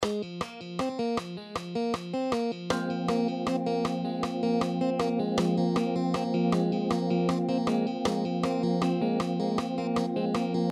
Example 4: Another 7/4